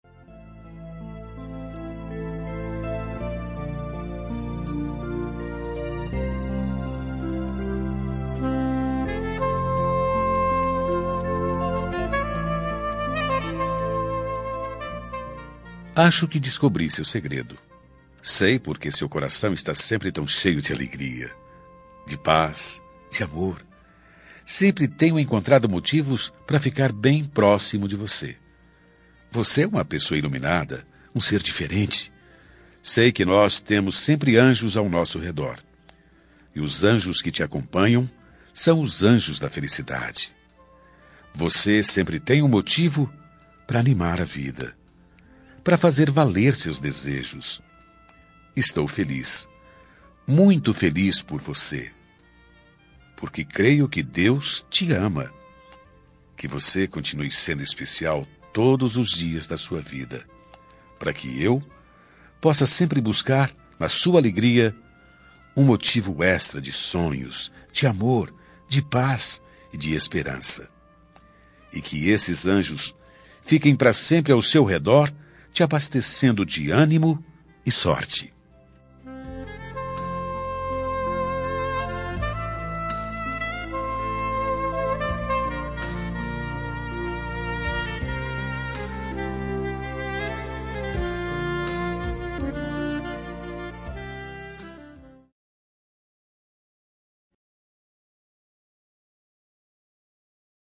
Aniversário Religioso – Voz Masculina – Cód: 85 – Anjos